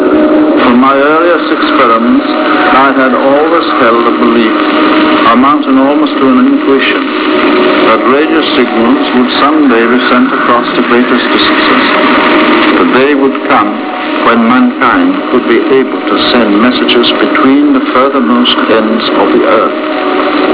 Great CRASHING SPARKS could be heard....
** Hear the Marconi Spark Gap calling All Ships at Sea **